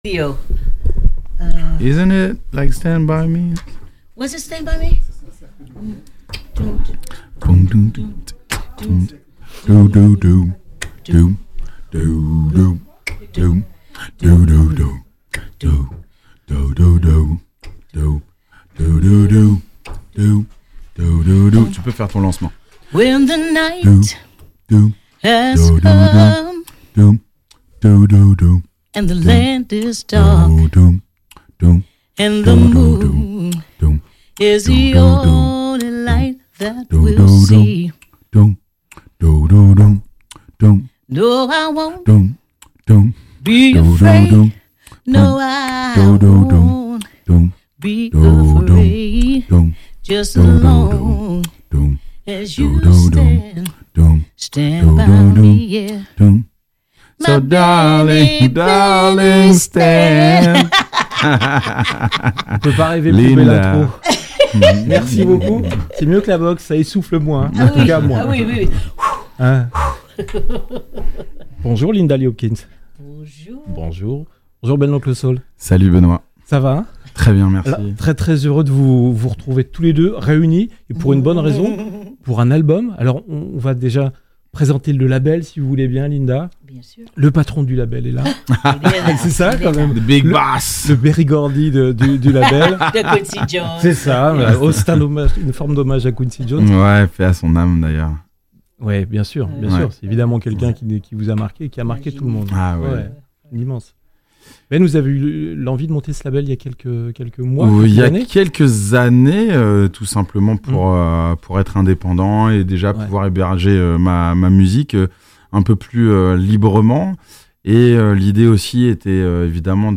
Interview Jazz Radio x Docks Live Sessions